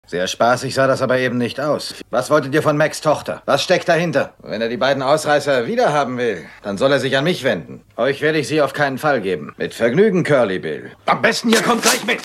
Lex Barker: Old Shatterhand,  Synchronschauspieler: Gert Günther Hoffmann
Hörprobe des deutschen Synchronschauspielers (192 Kb)